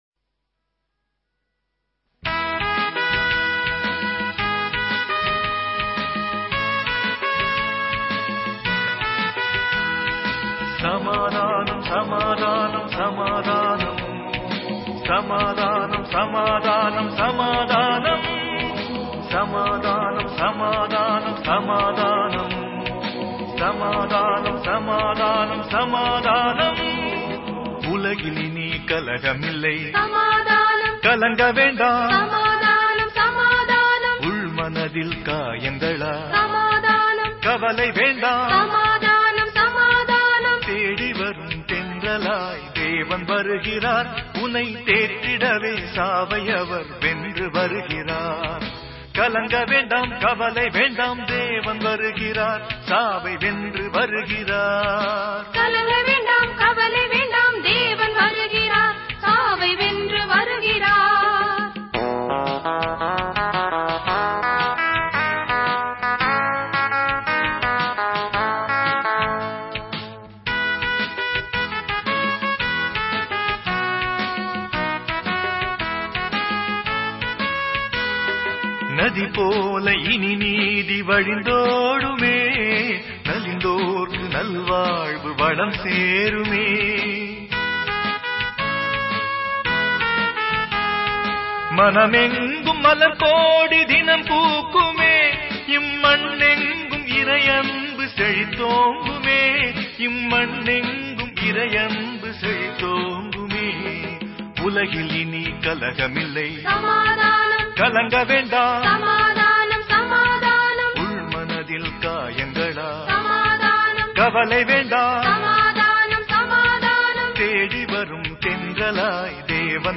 Directory Listing of mp3files/Tamil/Dramas/Dance Drama 6 VETRI THIRUMAGAN/ (Tamil Archive)